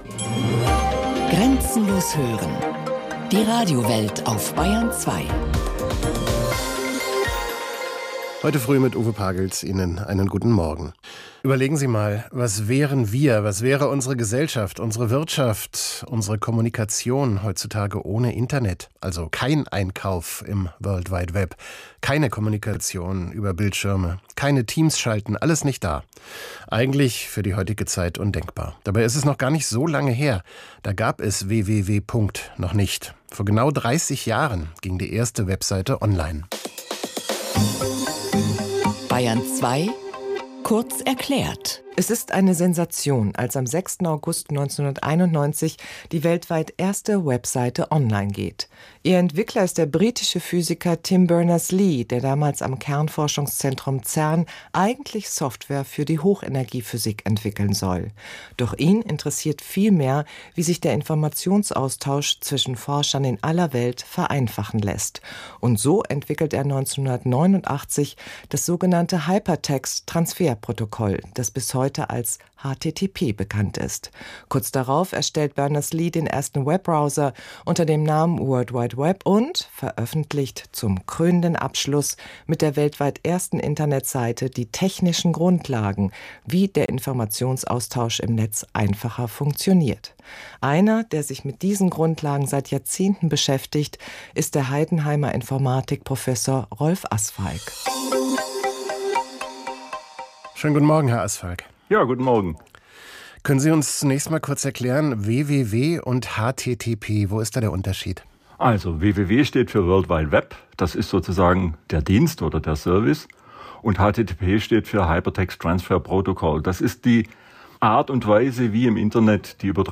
BR2-InterviewCut.mp3